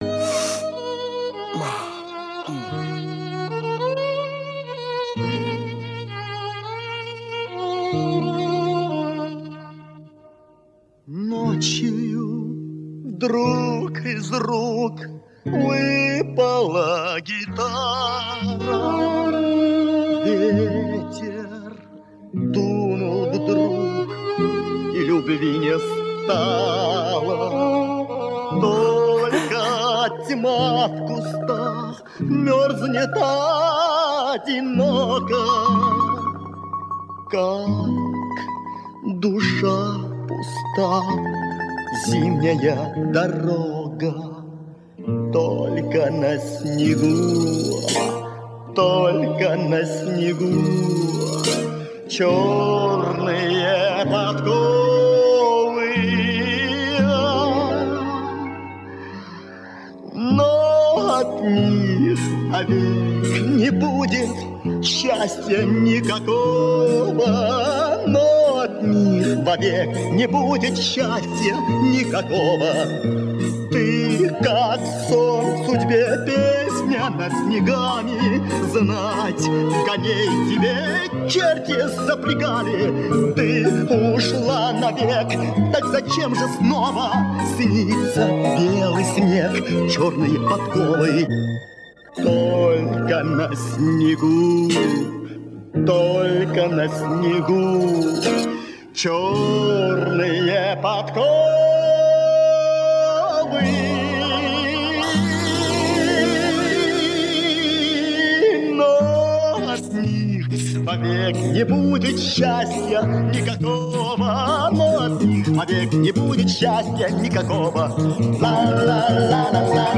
Примечание. Последний куплет почему-то не исполнялся.